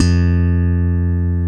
Index of /90_sSampleCDs/East Collexion - Bass S3000/Partition A/SLAP BASS-D